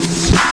13 REV CLP-L.wav